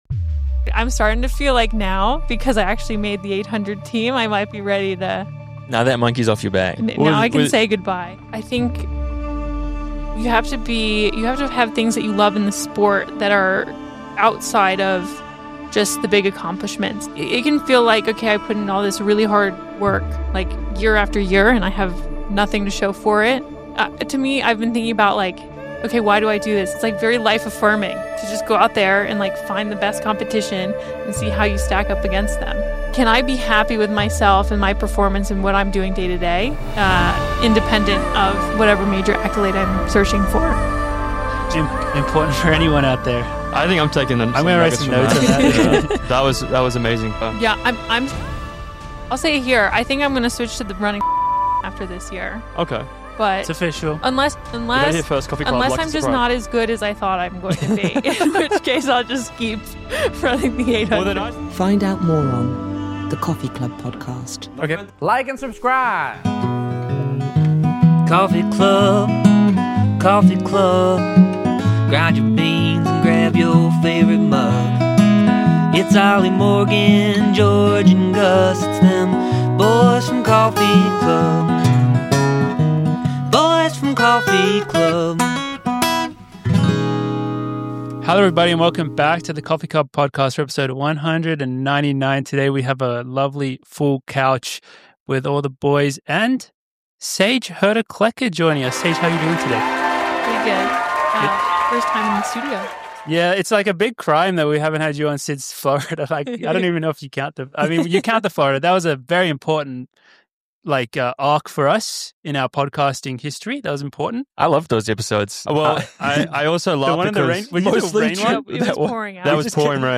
Play Rate Listened List Bookmark Get this podcast via API From The Podcast A few runner bois (Morgan McDonald, Oliver Hoare, and George Beamish) sitting down drinking coffee and having a chat.